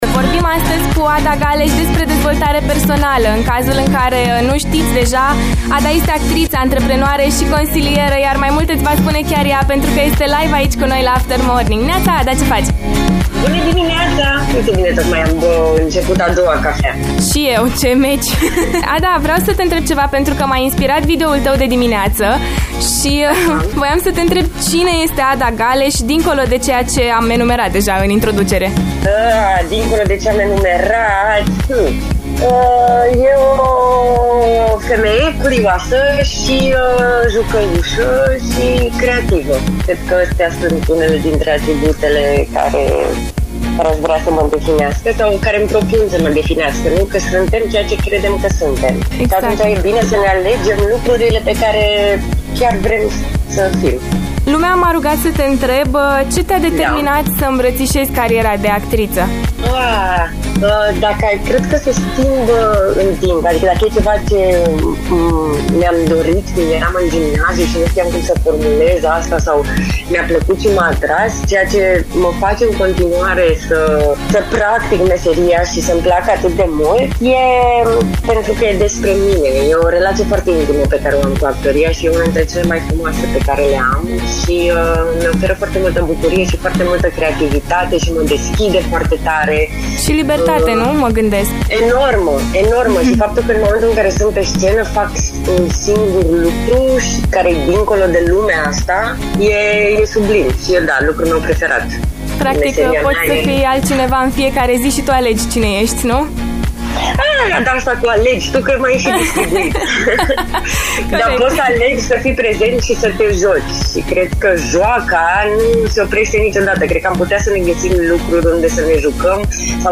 Home Emisiuni After Morning Cine suntem noi când suntem singuri? Interviu